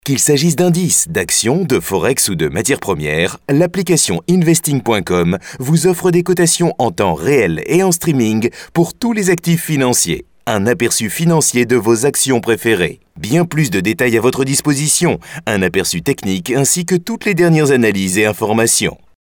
Sprechprobe: Werbung (Muttersprache):
French native (no accent) middle age male voice-talent since 1988, i have my own recording facilities and deliver in 2 to 6 hours ready to use wavs/mp3 files, paypal accepted, my voice is clear sounding serious but friendly at the same time !